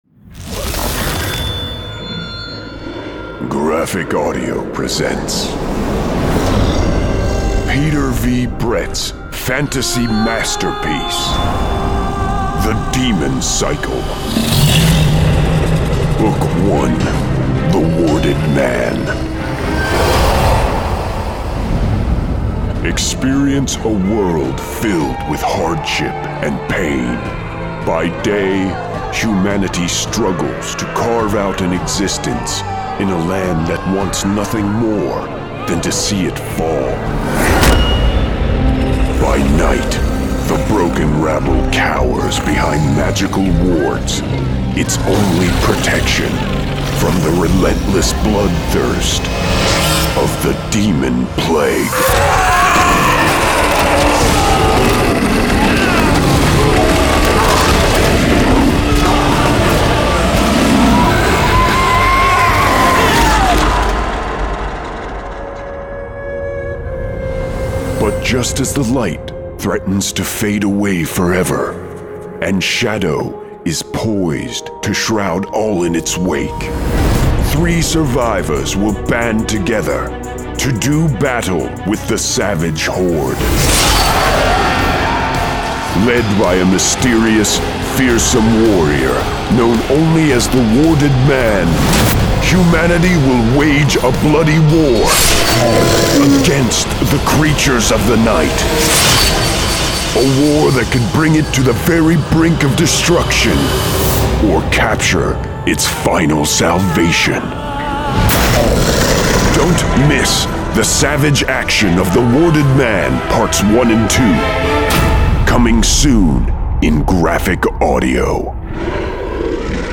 Full Cast. Cinematic Music. Sound Effects.
DEMONCYCLE0101-TRAILER.mp3